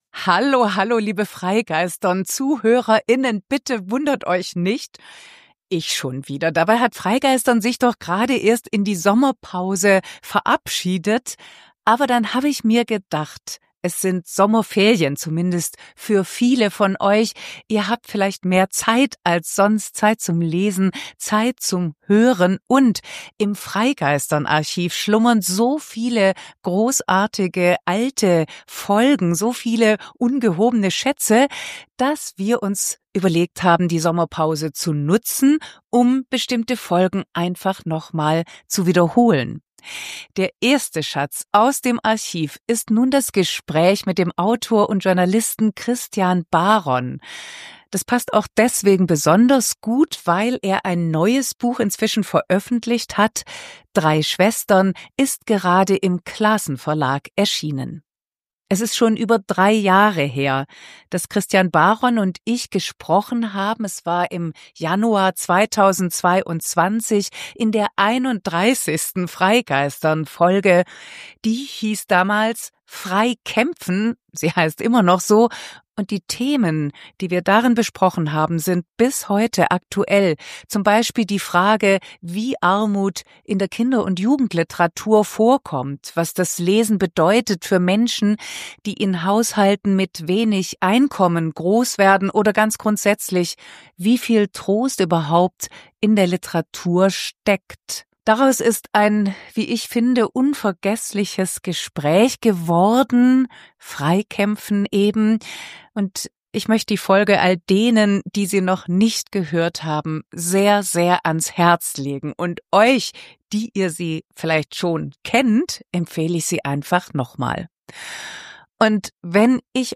Ein Gespräch über Kinderarmut in Deutschland, Scham, soziale Ungleichheit, Fantasie als Überlebensstrategie und darüber, wie Literatur trösten und politisch wirksam sein kann.